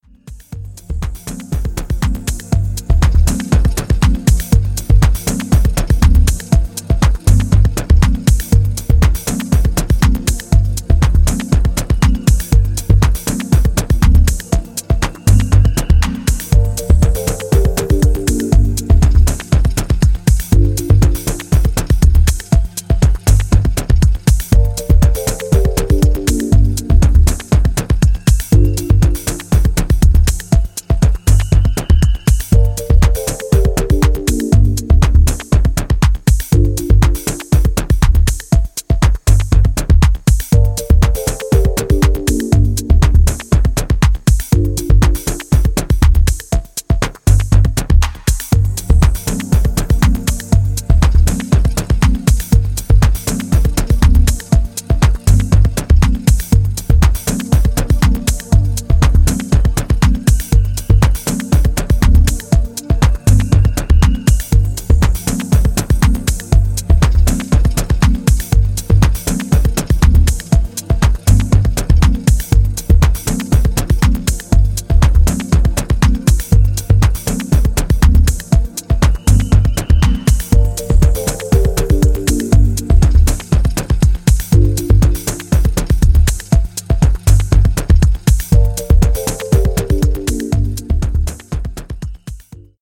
アナログ・ハードウェアを駆使して制作されたミニマル・ディープ・ハウスを披露しており